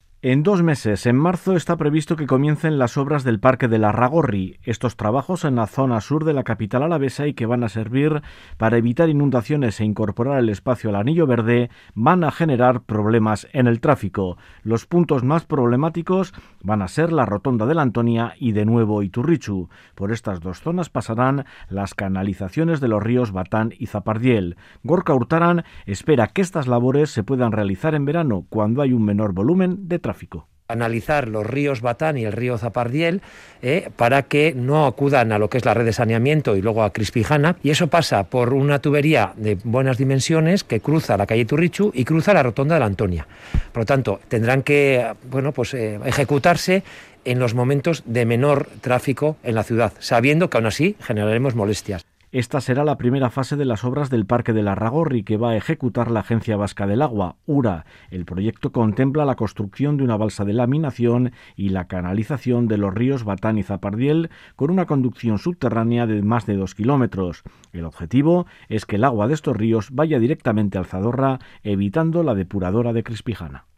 Esta actuación en la zona de Lasarte se iniciará en marzo, y el alcalde de Gasteiz, Gorka Urtaran, entrevistado en Radio Vitoria, ha asegurado que las obras en estos dos puntos críticos para el trafico por el Sur de la ciudad se realizarán en verano.